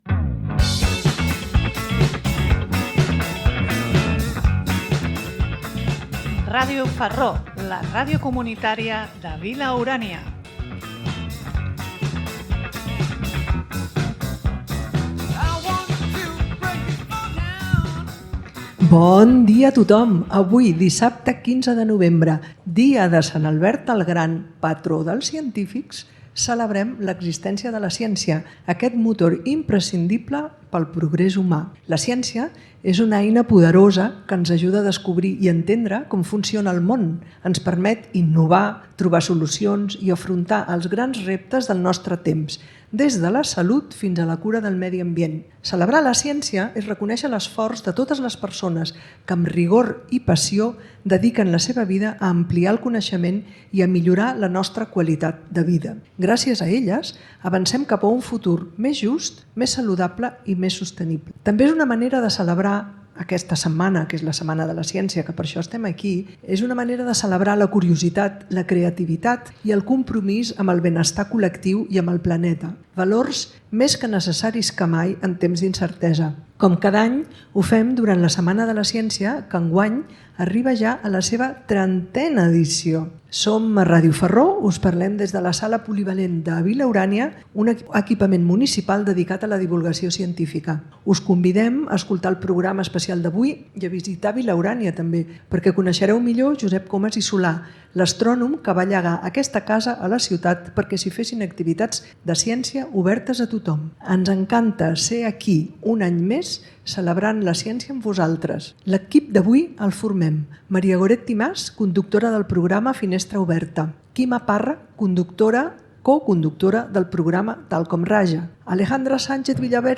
Un any més Ràdio Farró ha participat en les Festes de la Mercè de Barcelona, amb l’emissió d’un programa. Enguany des de la plaça Catalunya.